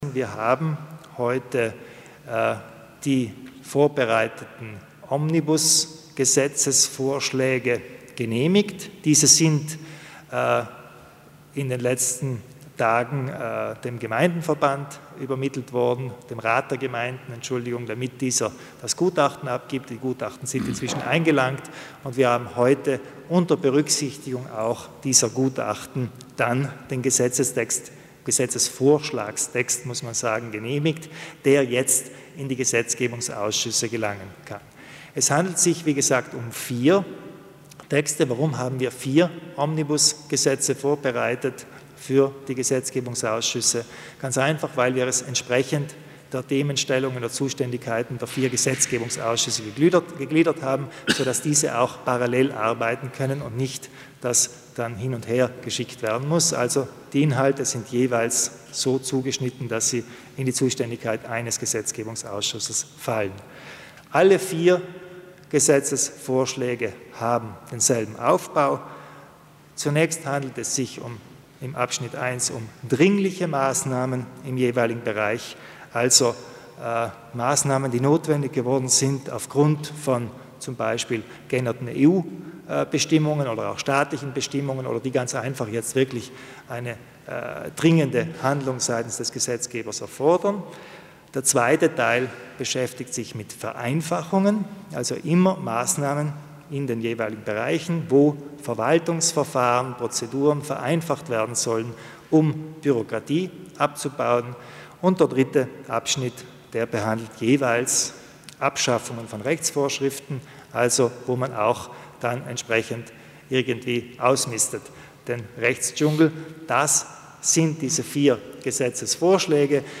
Landeshauptmann Kompatscher zu den Details des Omnibus-Gesetzesvorschläge